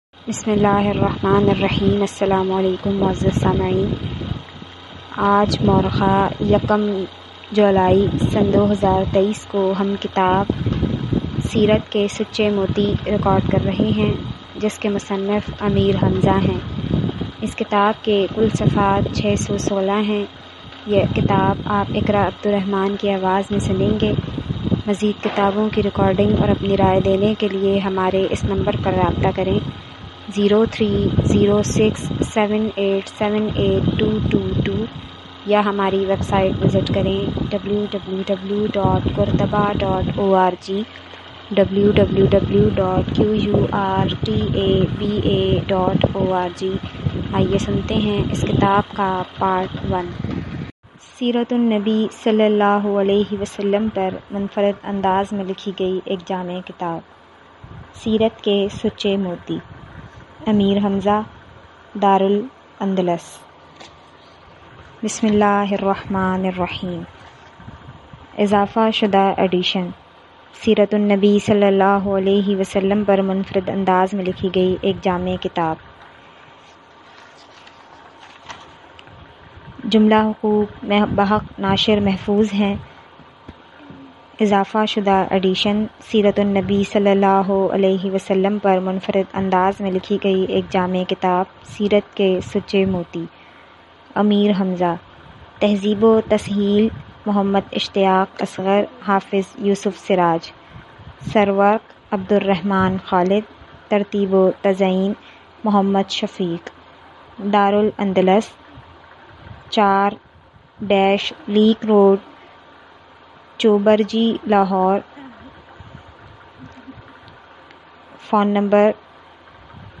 This audio book seerat k sachay moti is written by a famous author ameer hamza and it is listed under seeratun nabi category.